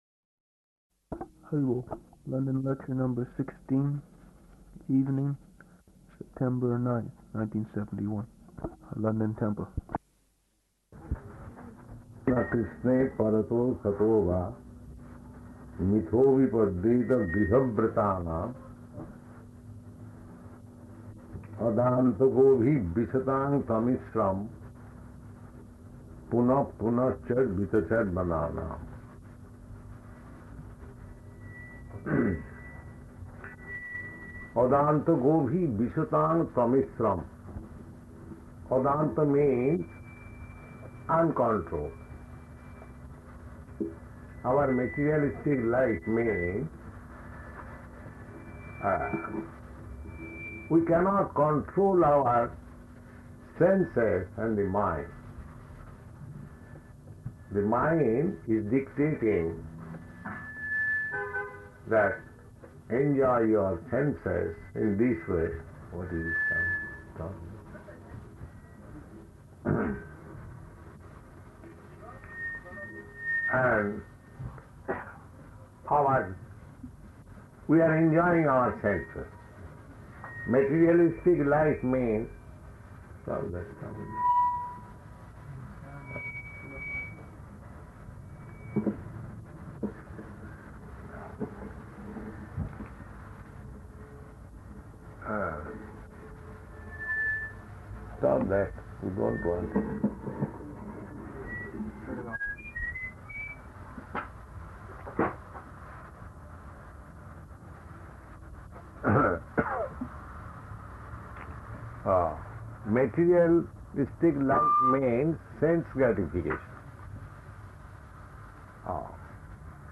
London lecture number 16, evening, September 9th, 1971, London temple.
[feedback] What is this sound?